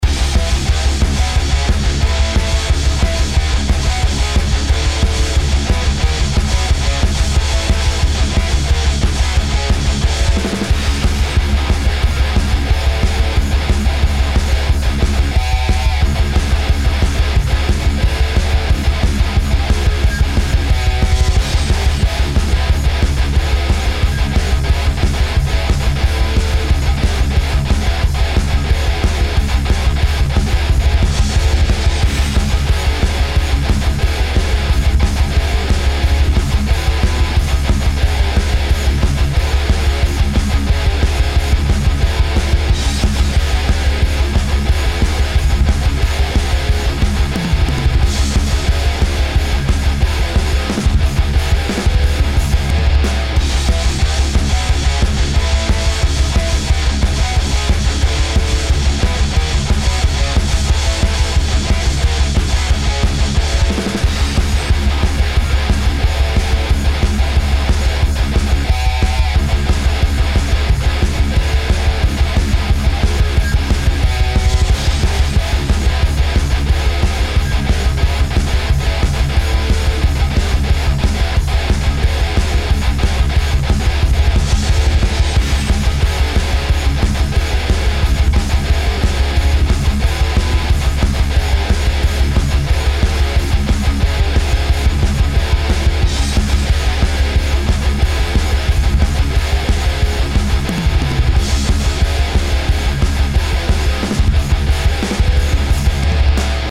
Le456, Impulses, Addictive drums.